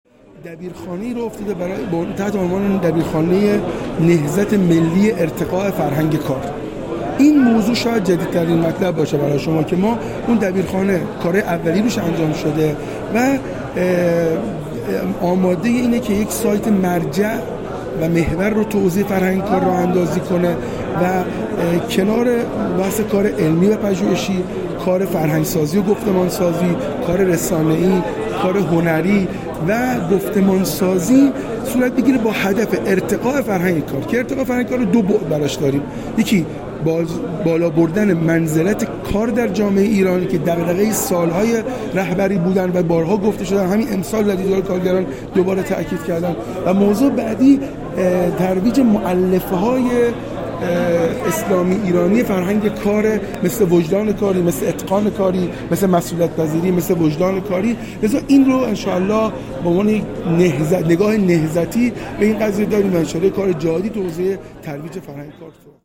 محمد چکشیان، معاون فرهنگی ـ اجتماعی وزارت تعاون، کار و رفاه اجتماعی در گفت‌وگو با ایکنا بیان کرد: سه برنامه در حوزه فرهنگی، اجتماعی و ورزشی برای مخاطب جامعه کار و تولید مورد توجه است.